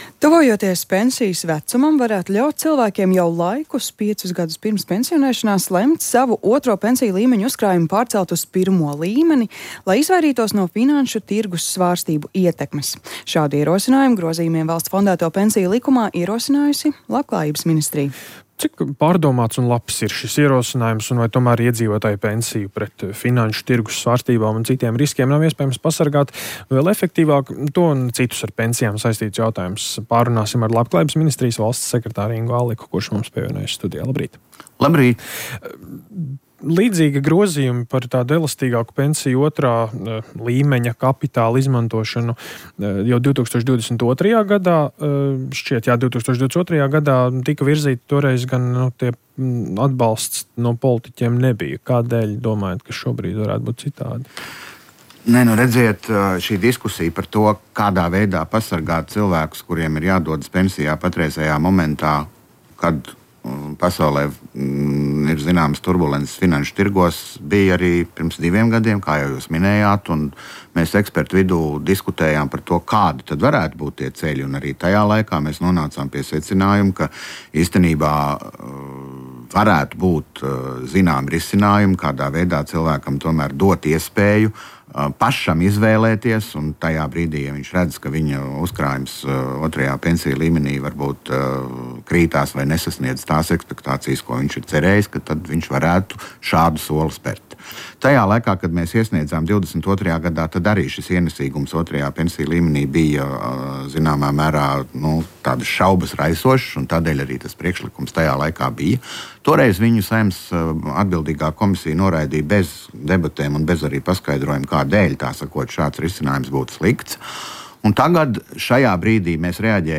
Vieglās valodas pētniece: Saprotama valoda – cilvēktiesību jautājums – Rīta intervija – Lyssna här